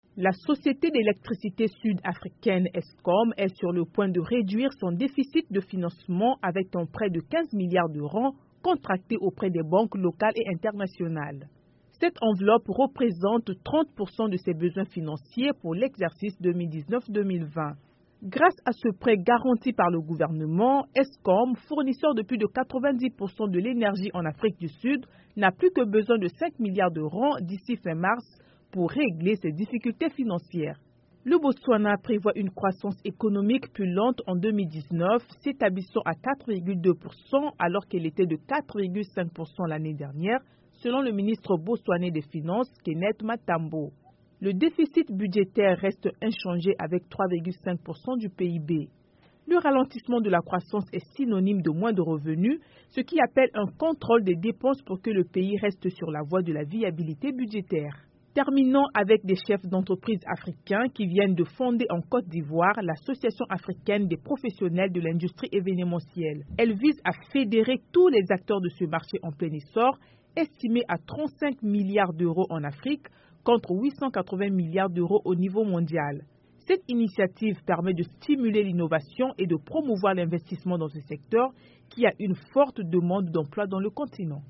Au micro de VOA Afrique, certains fonctionnaires expriment leur déception.